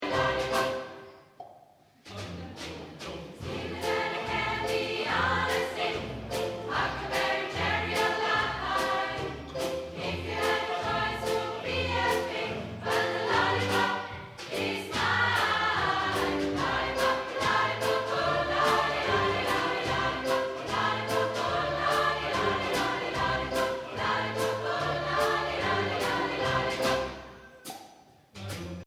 Live-Aufnahmen Konzert Schneisingen 2007
Live-Konzert vom 20./21. Januar 2007 in der kath. Kirche Schneisingen.
Lollipopp Chor